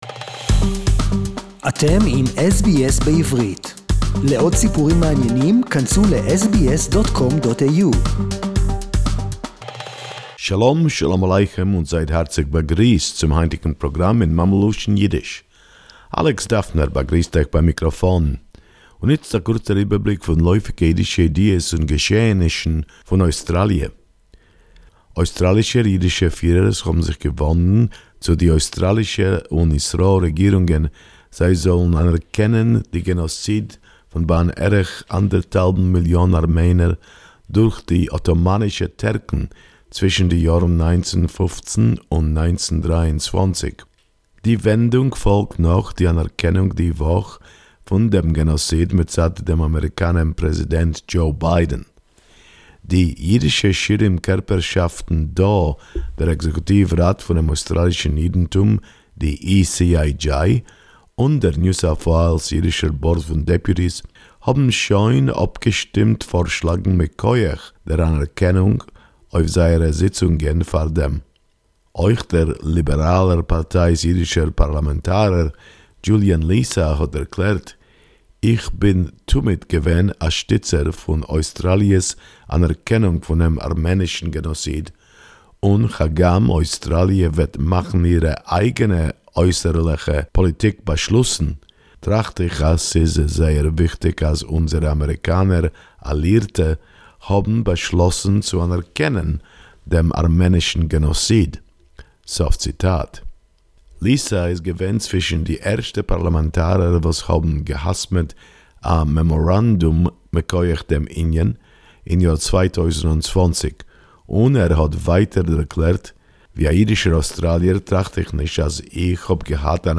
Jewish Australians accepted the offer of dual citizenship with Austria SBS Yiddish report